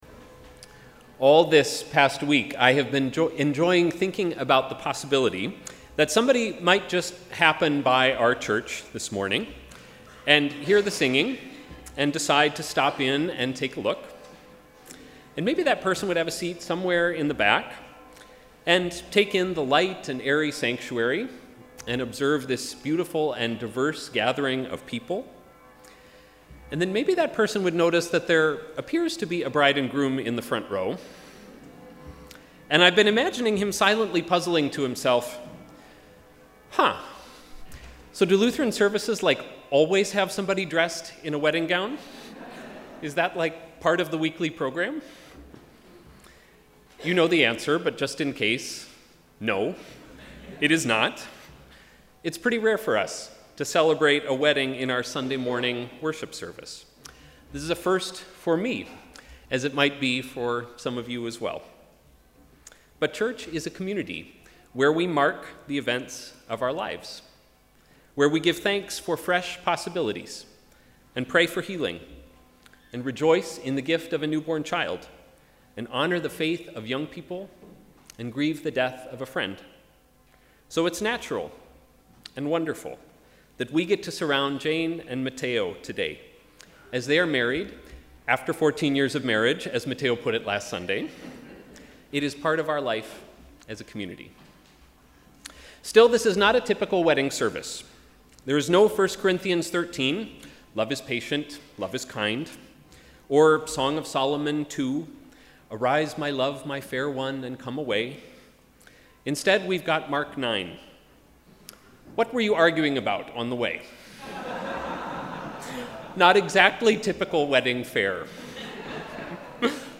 Sermon: ‘At home in love’